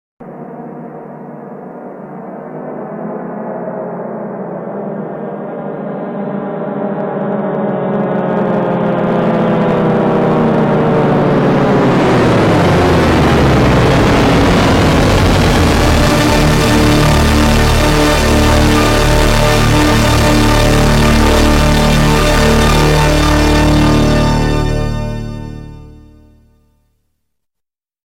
Loudest sound on TikTok v2 sound effects free download